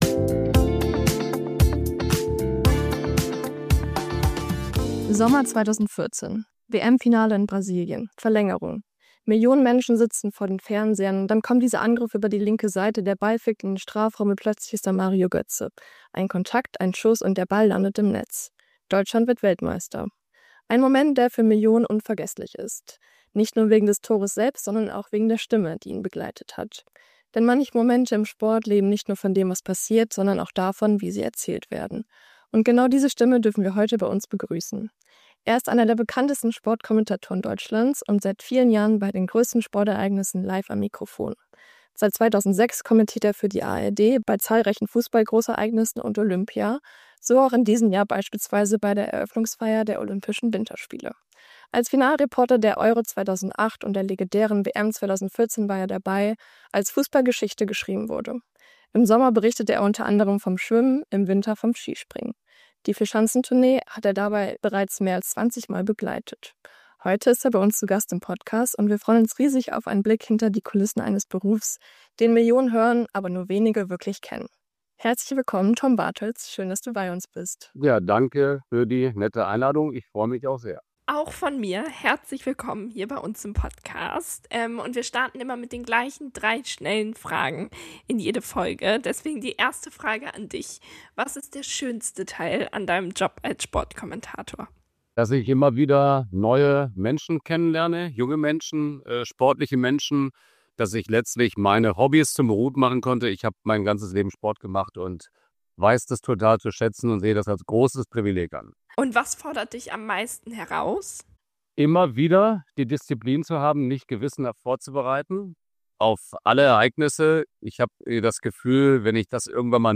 Genau diese Stimme ist Gast in unserer neuen Podcastfolge: Tom Bartels. In der Folge sprechen wir über die intensive Vorbereitung, Verantwortung und die Drucksituation bei seinen Live-Kommentaren, welche Rolle KI im Sportjournalismus spielen wird und darüber, welche Bedeutung Sport in der aktuellen Krisenzeit hat. Tom spricht offen darüber, wie er mit Shitstorms und Ausnahmesituationen umgeht, wie zum Beispiel der Terrornacht von Paris, während der er unmittelbar neben dem Tatort ein Freundschaftsspiel kommentierte.